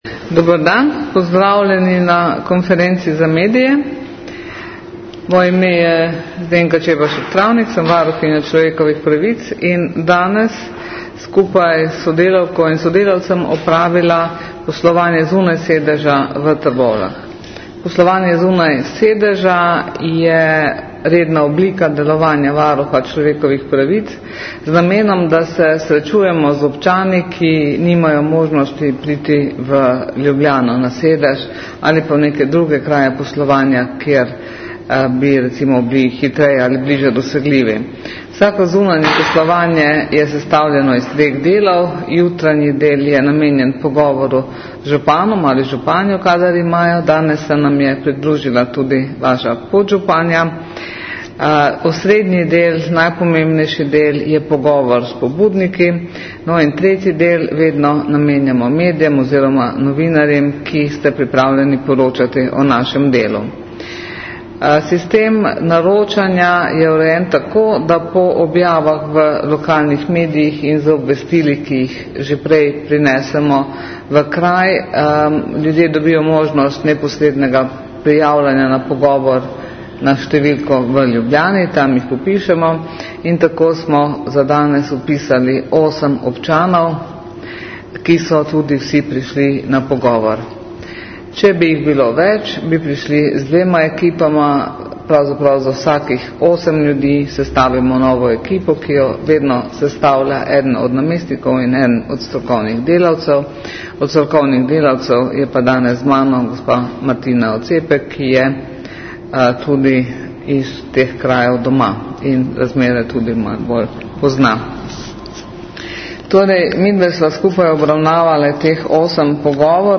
Varuhinja je ugotovitve poslovanja predstavila na krajši novinarski konferenci. Zvočni posnetek novinarske konference (MP3).